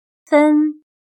/fēn/Minuto